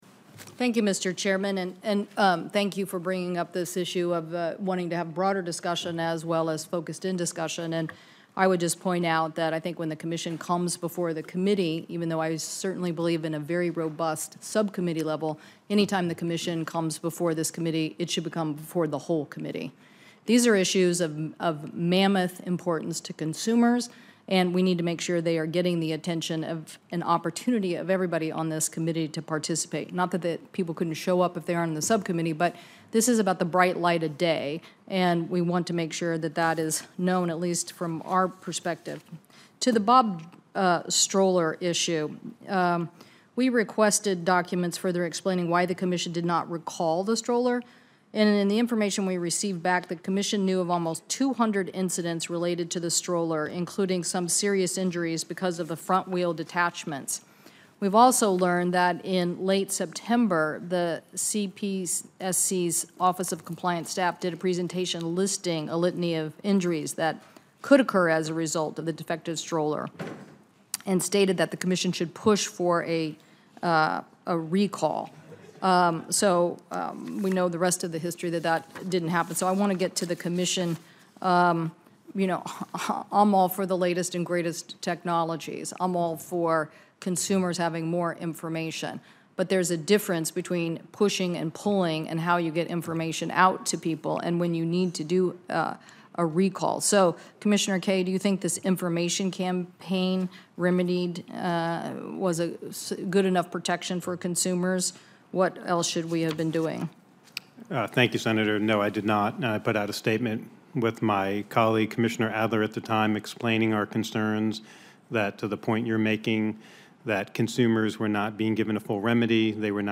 commerce-cpsc-qanda-audio&download=1